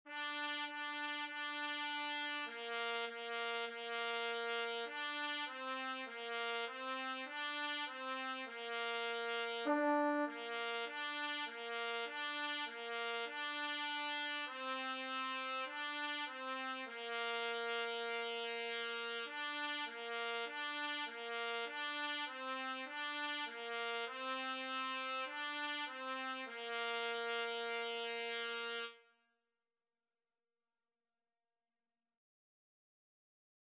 4/4 (View more 4/4 Music)
Bb4-D5
Beginners Level: Recommended for Beginners
French Horn  (View more Beginners French Horn Music)
Classical (View more Classical French Horn Music)